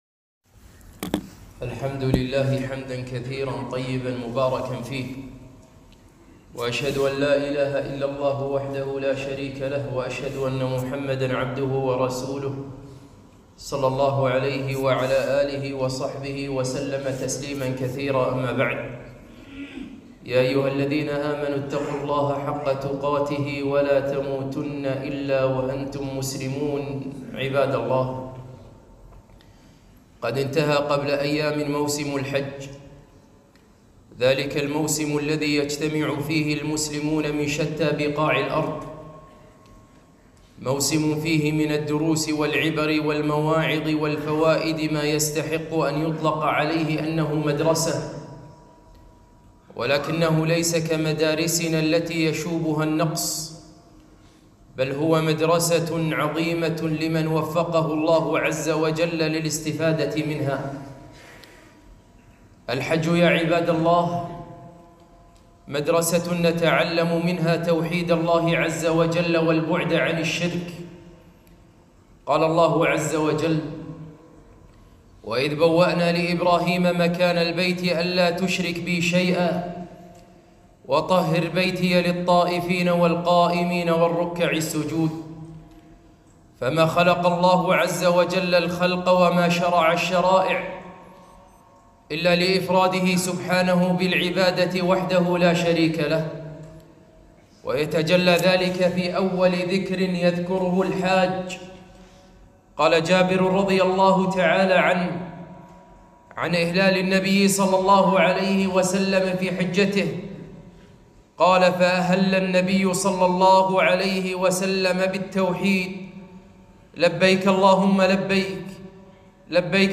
خطبة - مدرسة الحج ١٤٤٠هـــ